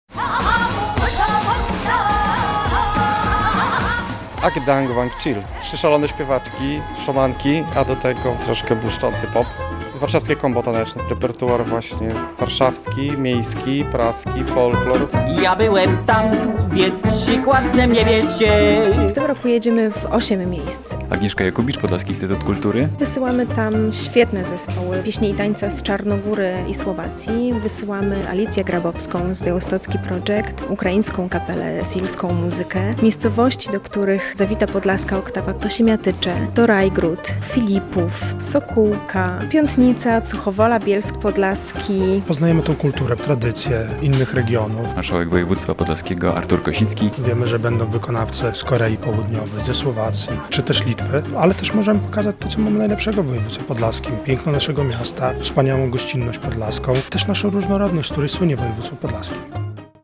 17 zespołów z całego świata przyjedzie w tym roku na Podlaską Oktawę Kultur - relacja